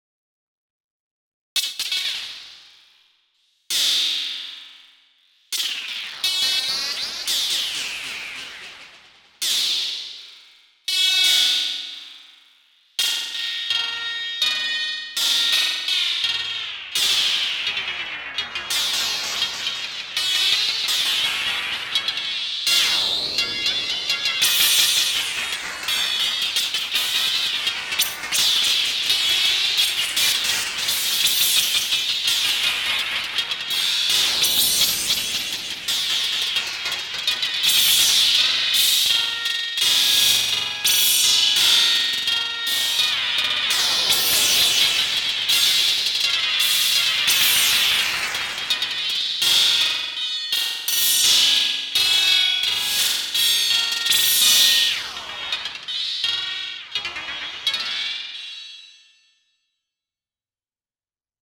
Here’s my attempt at an all CY Alloy track!
CY Alloy is fine for hats, but not much else! I got a few sweepy effects out of it and tones, but they lose lower frequencies real fast.
This is six tracks of CY Alloy all together, all with Overdrive maxed out and routed through FX. I’m relying on the filter and tracking a lot for tonal bits, and a few Euclidean sequenced rhythmic bits.
FX has a slewed S&H LFO on the Delay Time parameter, and an Exponential Trig Mode One LFO on the Delay width.
I like the playfulness, and I can detect harmonies in there, nice!